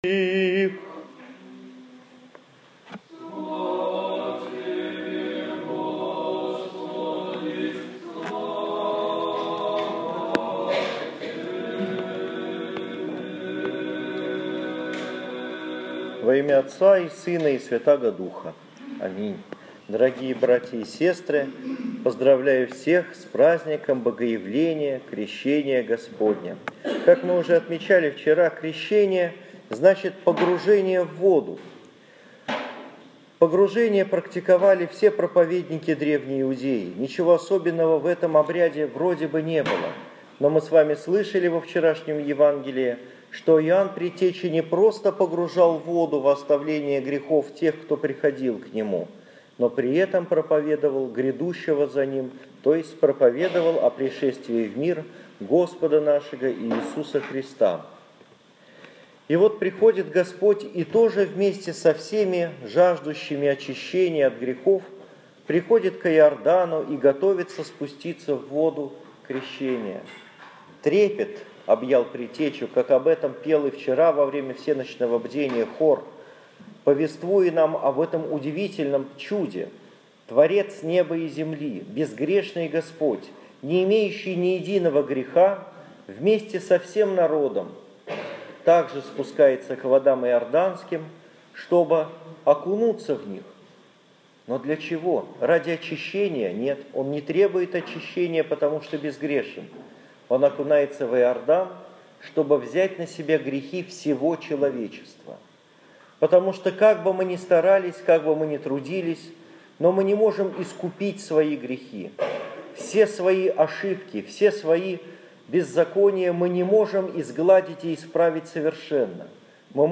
Моя проповедь 19 января 2015 года в нашем Петропавловском храме (Шуваловский парк, Парголово, СПб) после Евангелия на литургии святителя Василия Великого.